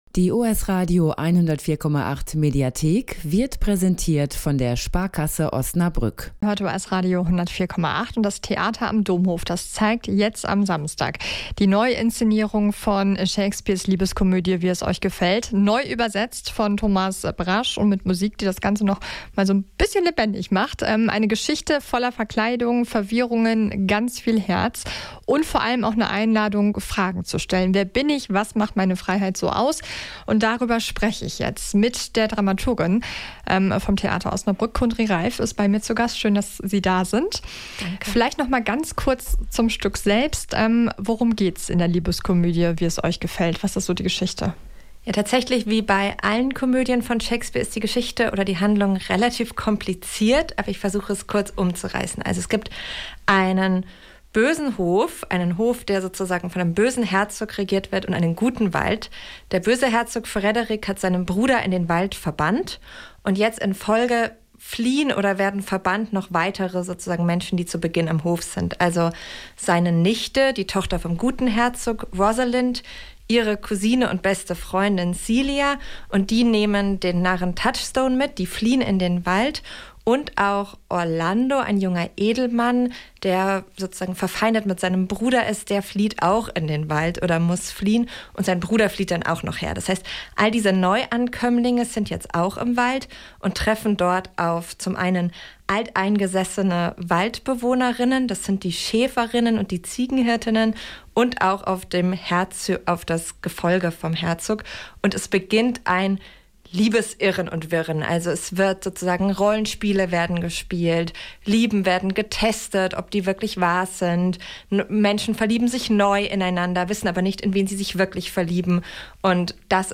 zu Gast im Studio